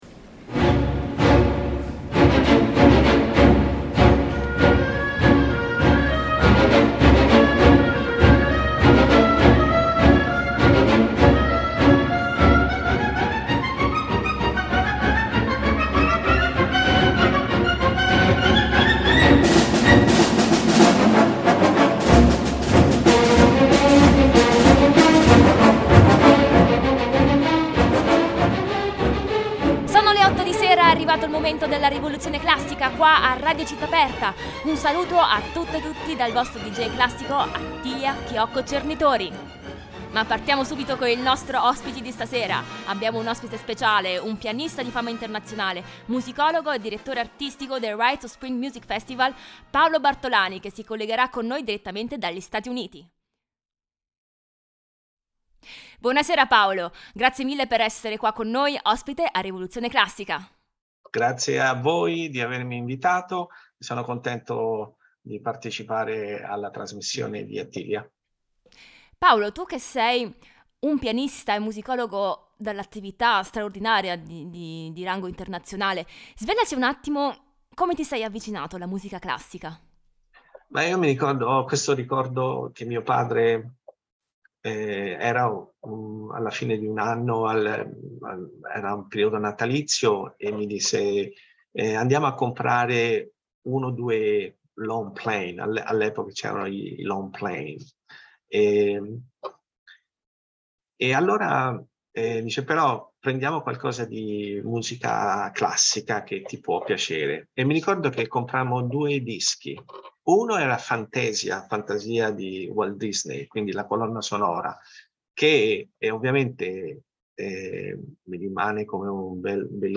violino
violoncello
pianoforte